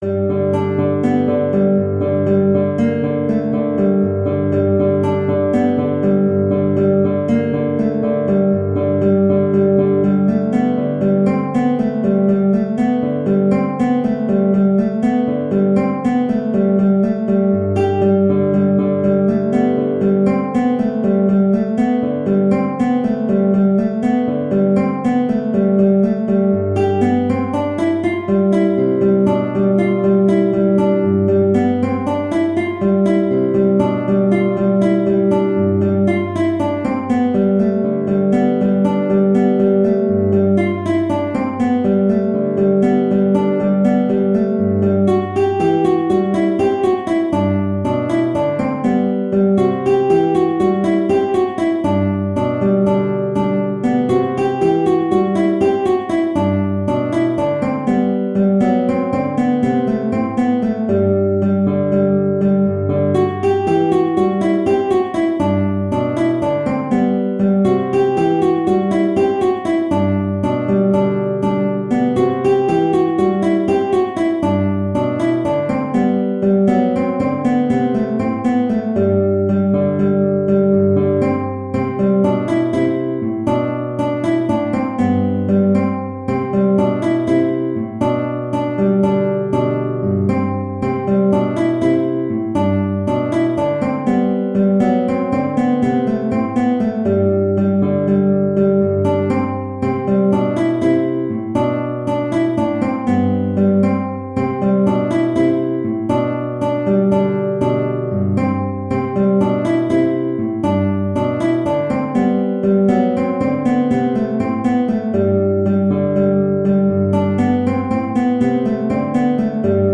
9/16 (View more 9/16 Music)
E3-G5
Guitar  (View more Intermediate Guitar Music)
Traditional (View more Traditional Guitar Music)
world (View more world Guitar Music)